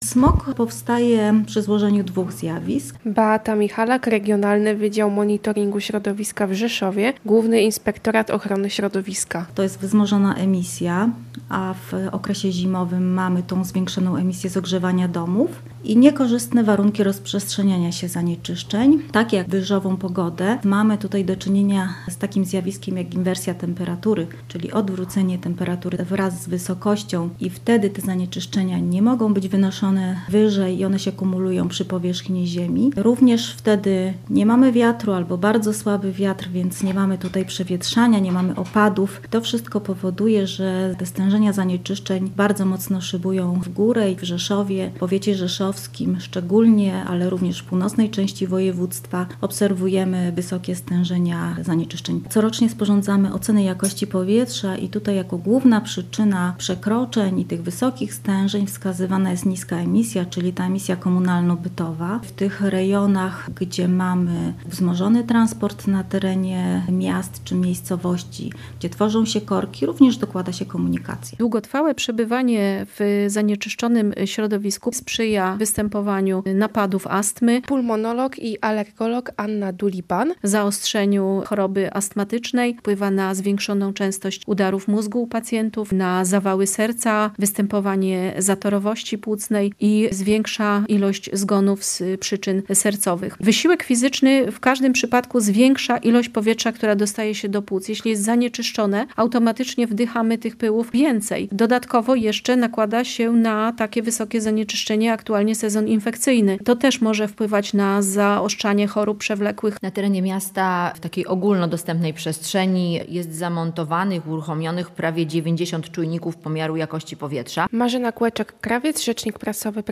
Alert RCB: Zła jakość powietrza w regionie • Relacje reporterskie • Polskie Radio Rzeszów